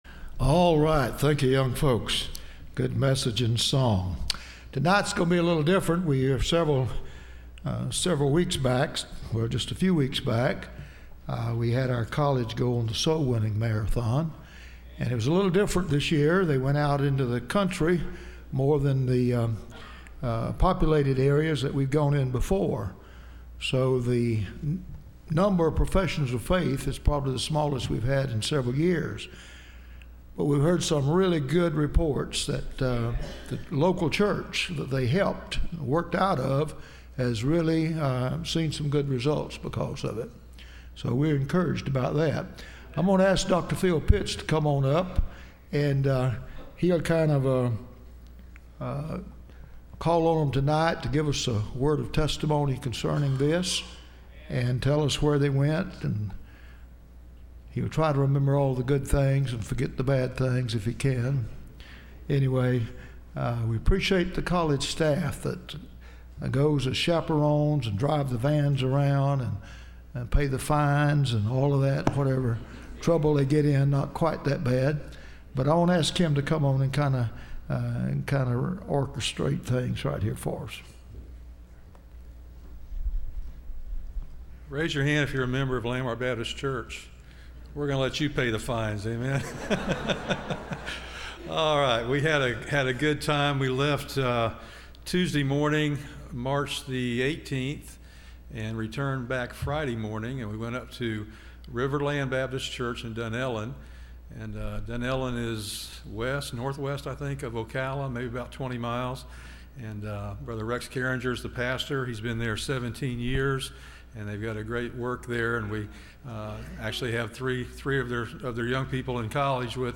Testimonies
Service Type: Wednesday College